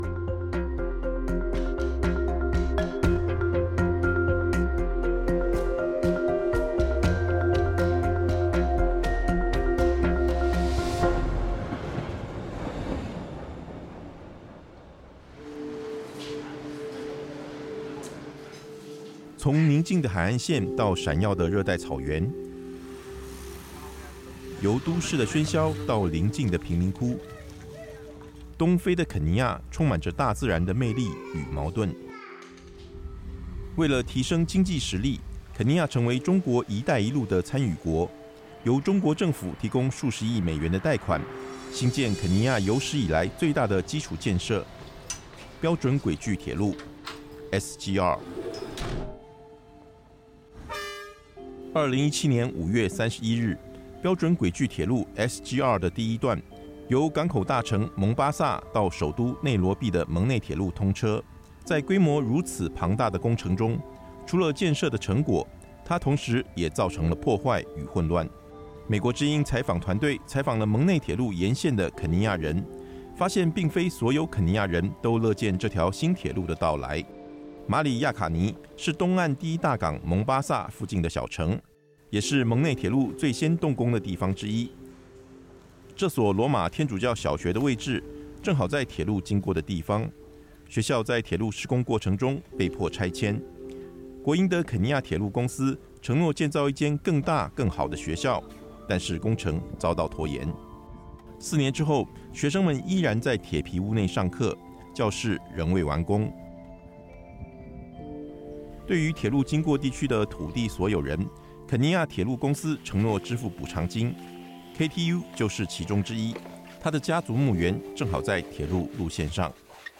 但是建造过程中，土地的征收以及当地工作机会分配等问题引发了许多纠纷，部分肯尼亚人表示，这条铁路只会让他们的生活变得更糟。请看“中国标准铁路在肯尼亚的土地与劳资纠纷”专题报道上集。